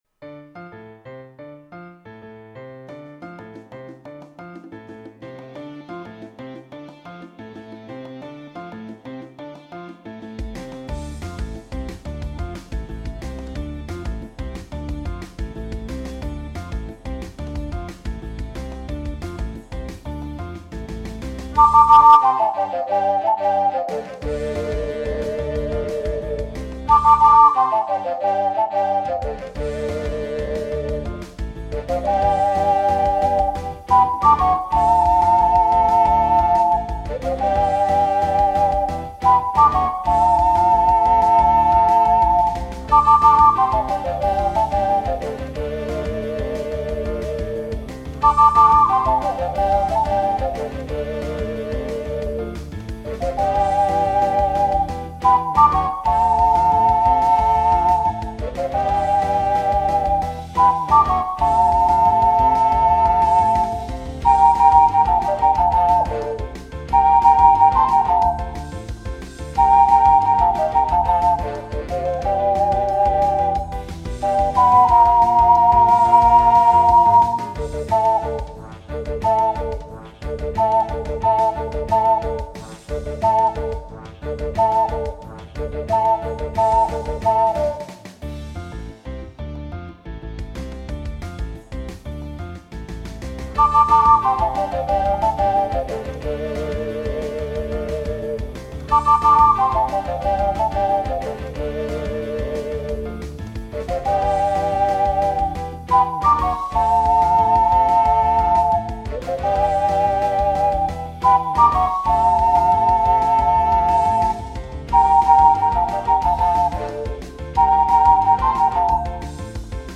＊試奏は雑に録音・編集したので、参考程度と思ってください。